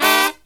FALL HIT09-R.wav